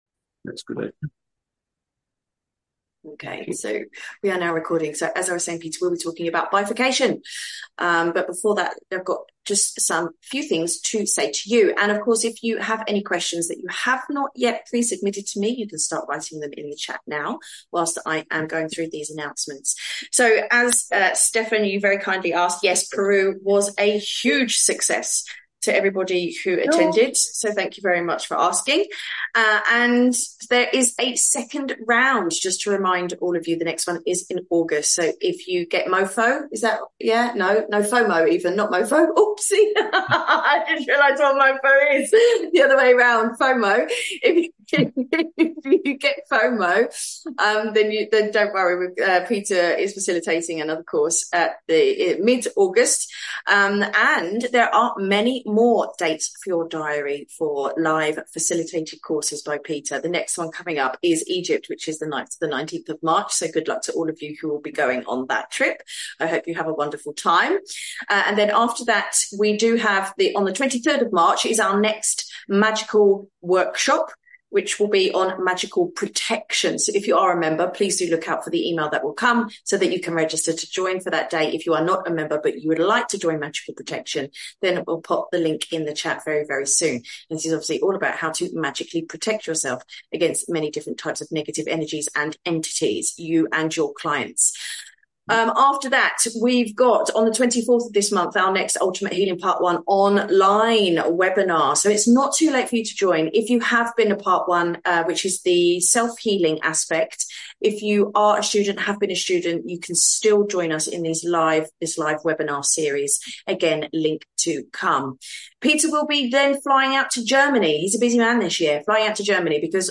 If you haven't already, please read my previous article on bifurcation and watch the video before watching this Q&A call.